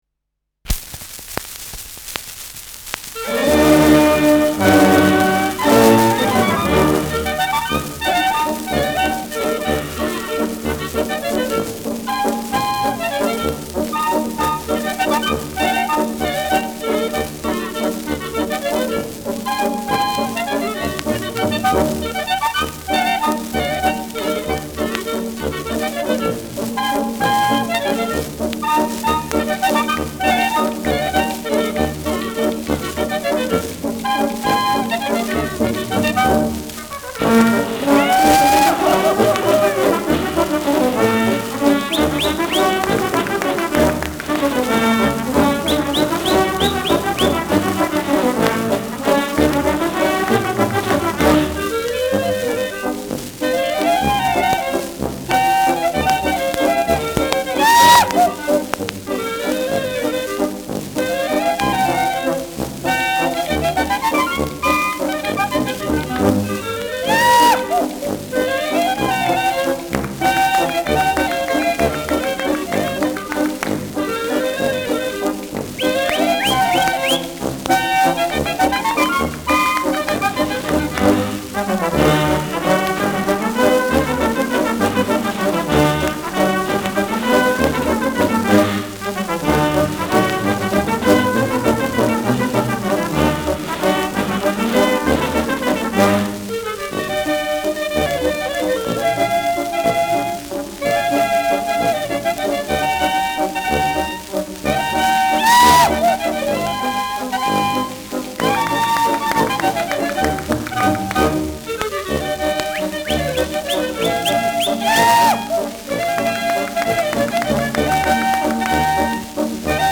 Schellackplatte
Juchzer, Pfiffe, Klopfgeräusche, Ausrufer am Ende.
[Berlin] (Aufnahmeort)